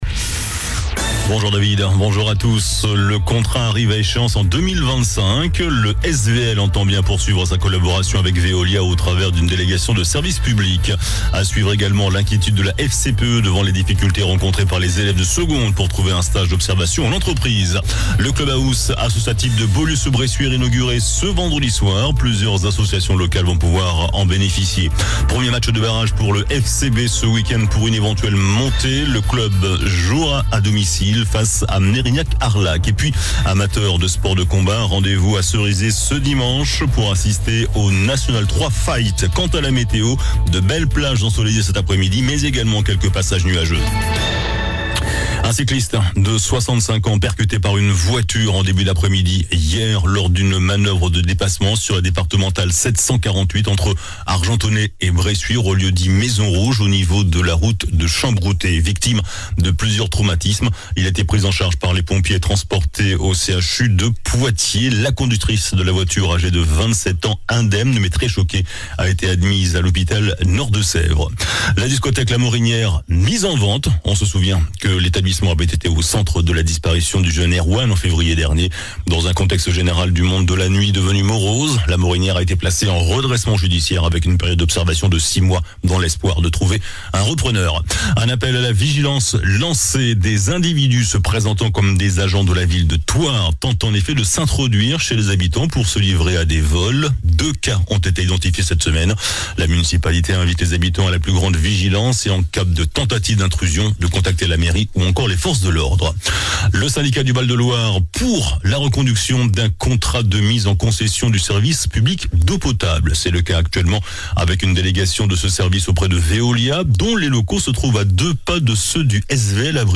JOURNAL DU VENDREDI 07 JUIN ( MIDI )